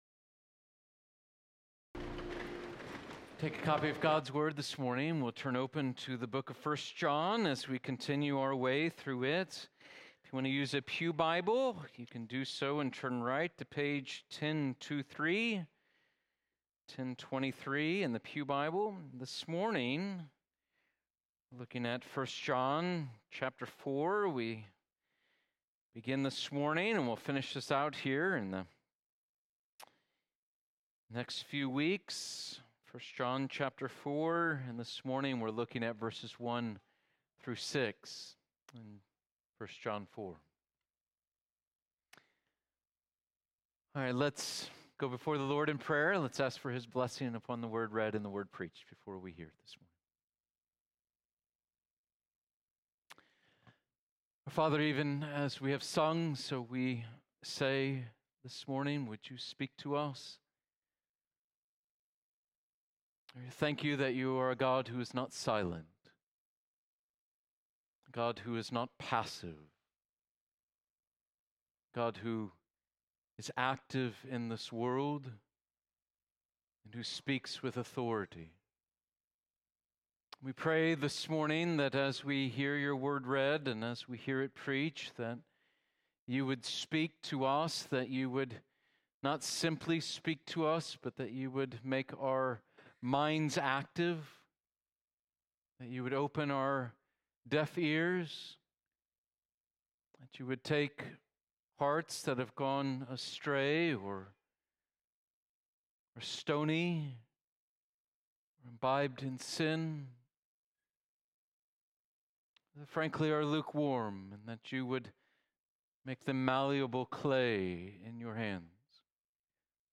Sermons | University Reformed Church - East Lansing, MI